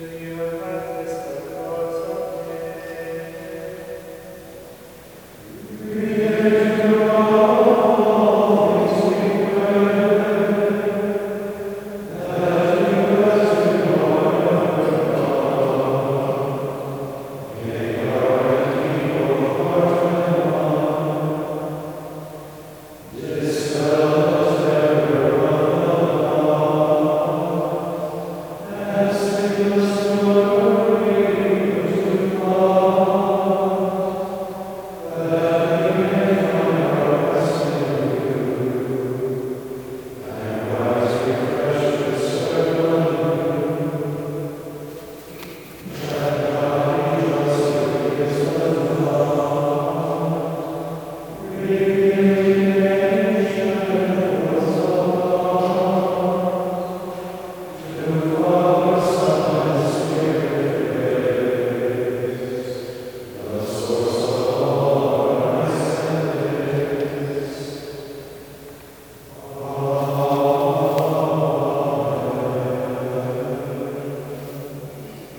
The chanting that the monks do is mesmerizing and soothing.
One hymn that they sing each evening demonstrates the spirit of the service.   Although I only had my cell phone, I tried recording it and although it is a poor recording, it gives you the idea of how peacefully this service closes the day.
Saint-Meinrad-Compline-Hymn.mp3